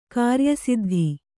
♪ kāryasiddhi